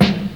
• Classic Tight Low End Hip-Hop Acoustic Snare Sample G Key 318.wav
Royality free steel snare drum sound tuned to the G note. Loudest frequency: 879Hz
classic-tight-low-end-hip-hop-acoustic-snare-sample-g-key-318-ukv.wav